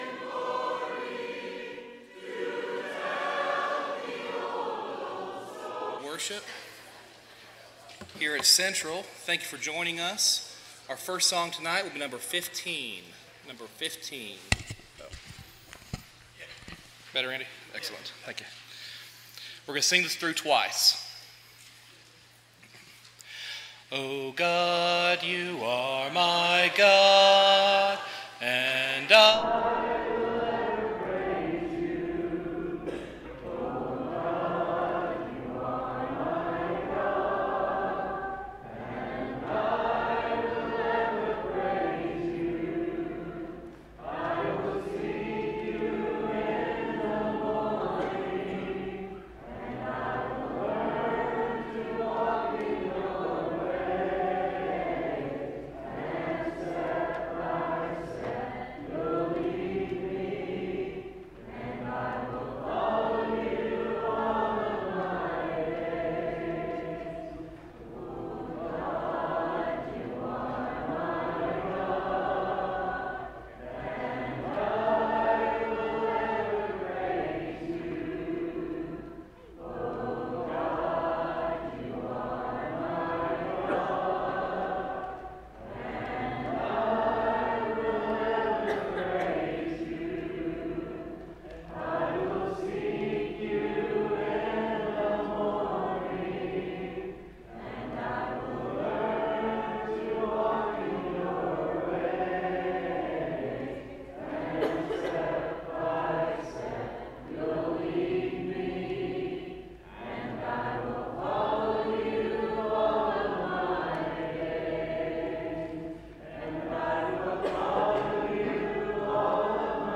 (Matthew 6:33, English Standard Version) Series: Sunday PM Service